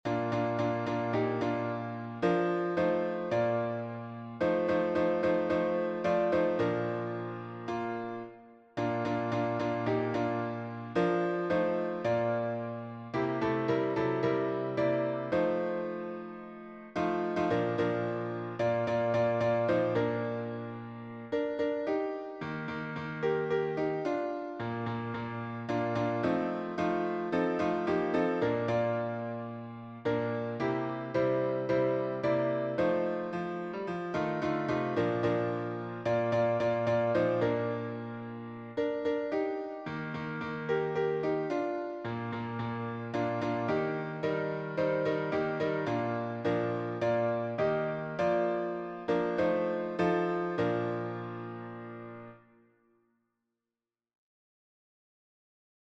Words and music by Charles Austin Miles (1868-1946), 1910 Key signature: B flat maj